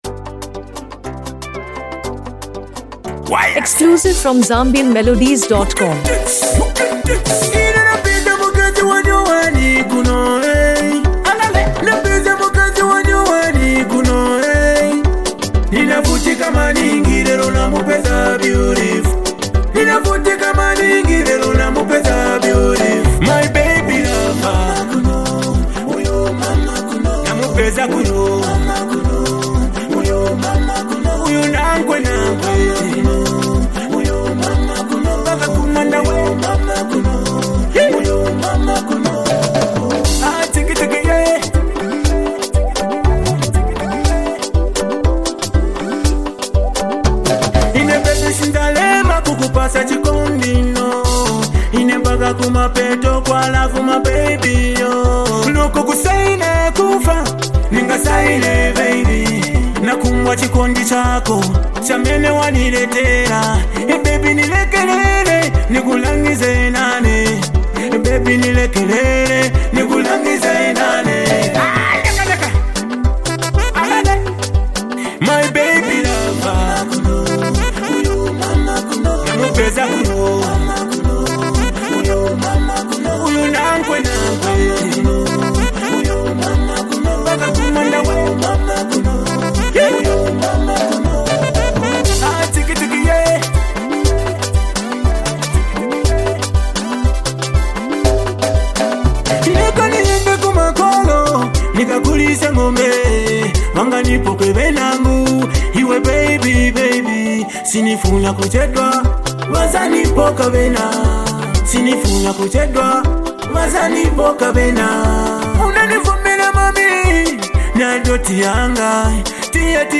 A Soulful Blend of Emotion and Rhythm
Known for his smooth vocals and relatable lyrics